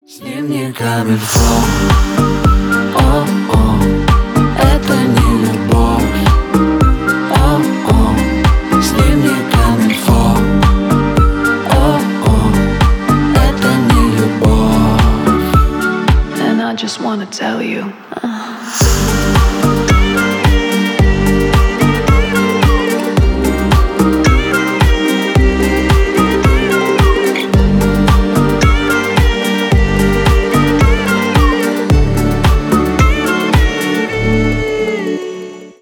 • Качество: 320, Stereo
поп
свист
дуэт
медленные